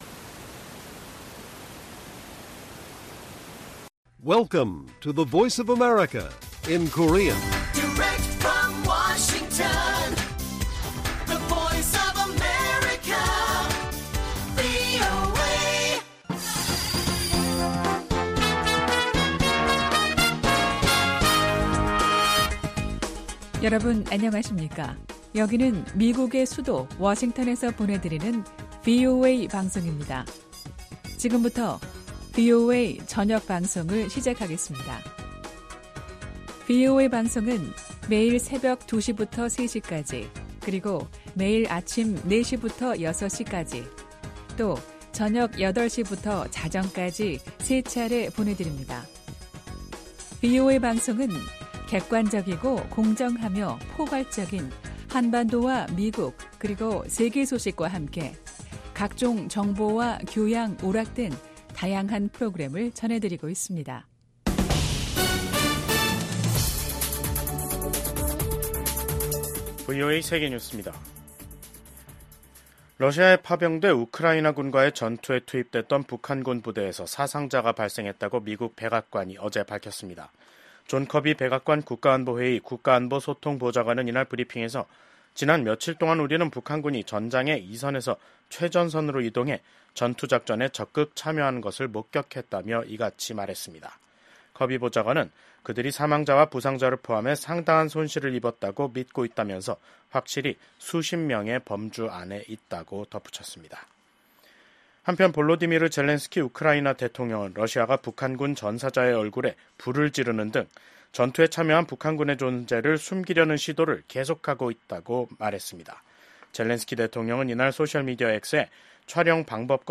VOA 한국어 간판 뉴스 프로그램 '뉴스 투데이', 2024년 12월 17일 2부 방송입니다. 미국 국무부는 미한 동맹이 윤석열 한국 대통령의 직무 정지에 영향을 받지 않을 것이라고 강조했습니다. 윤석열 대통령에 대한 탄핵소추안 가결에 따른 한국 내 권력 공백 상태가 미한 관계에 주는 영향은 제한적일 것이라는 분석이 나오고 있습니다.